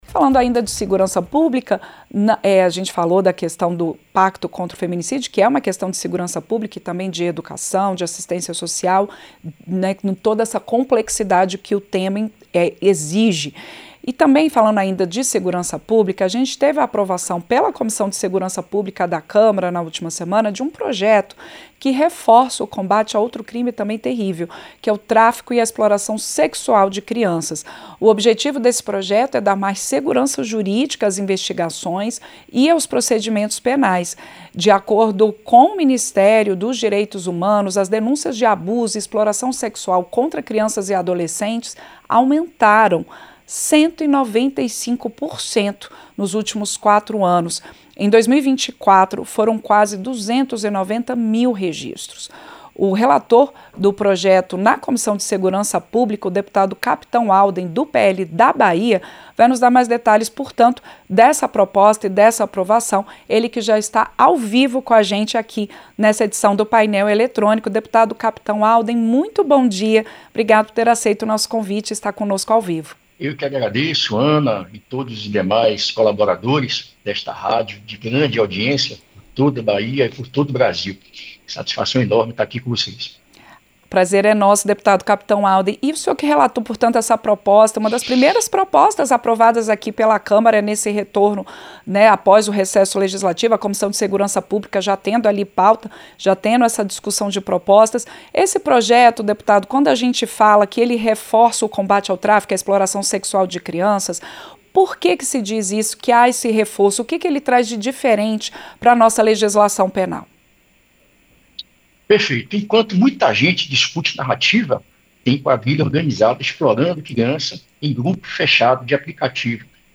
Entrevista - Dep. Capitão Alden (PL-BA)